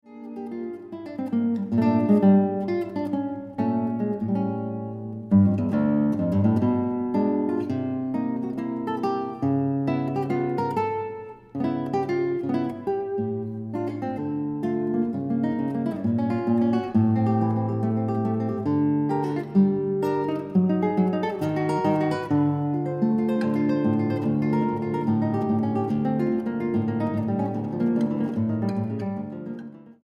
guitarra.
Poco vivo